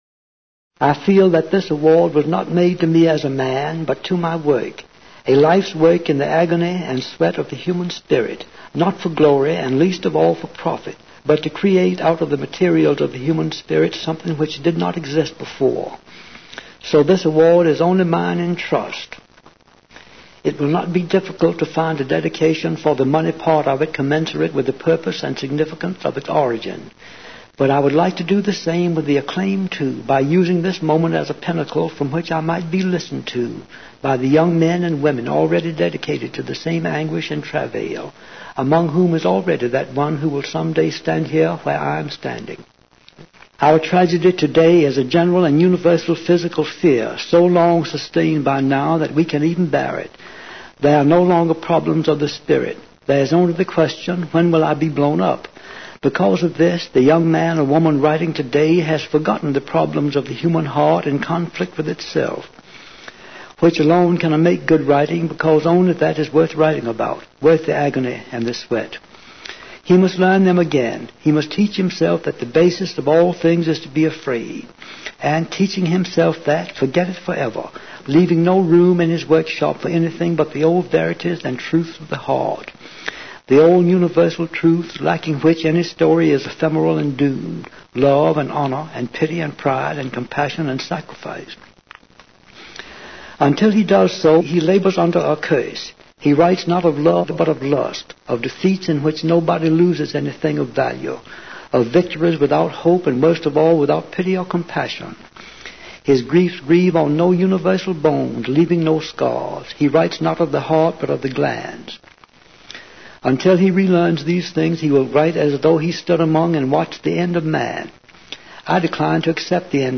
William Faulkner: Speech Accepting the Nobel Prize in Literature